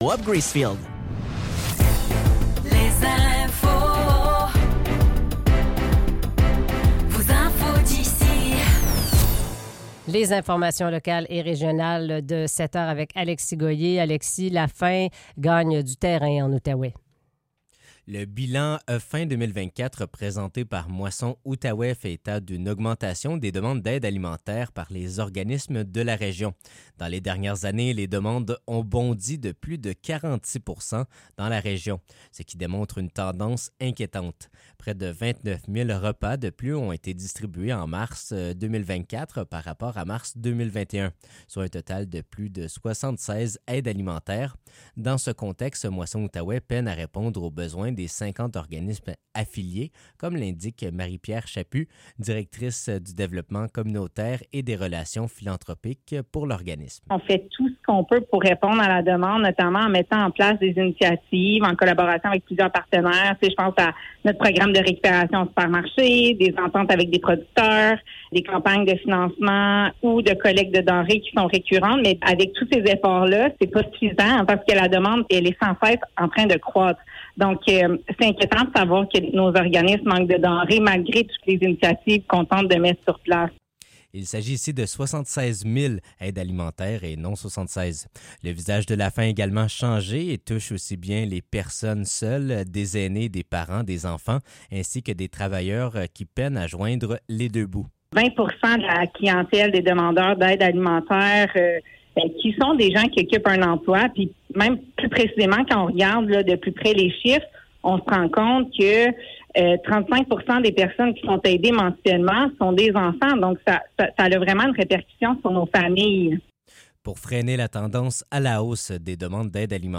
Nouvelles locales - 31 octobre 2024 - 7 h